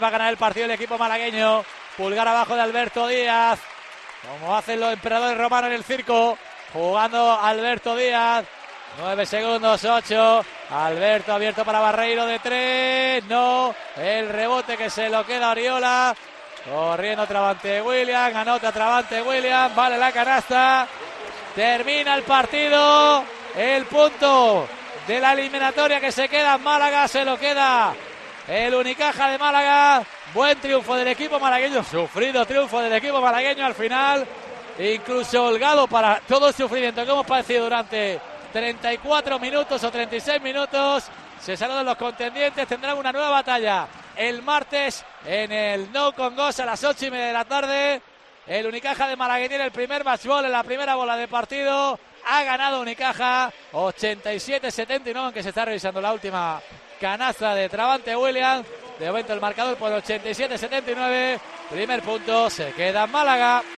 Así te hemos narrado el final del partido entre Unicaja y Baxi Manresa (87-79)